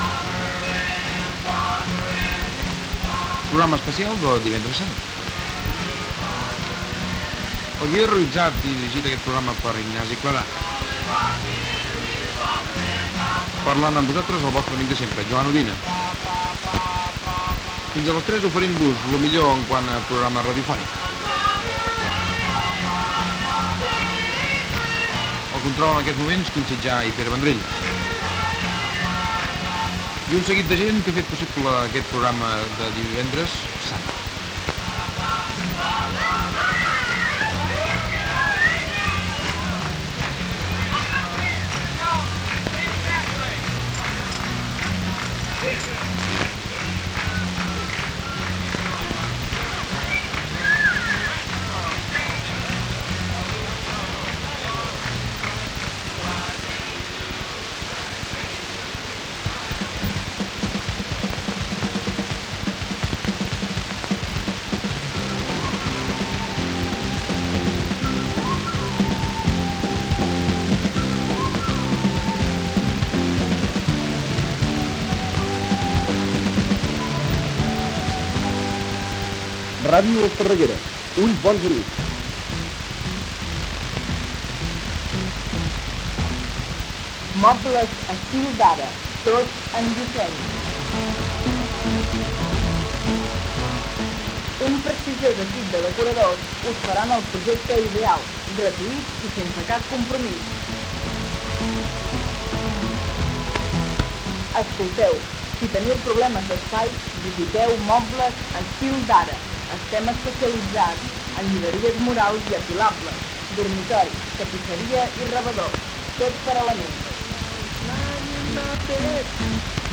Equip del programa i comiat, indicatiu de l'emissora, publicitat, indicatiu i anunci del programa especial d'EMUC sobre la Passió d'Esparreguera del divendres sant.
FM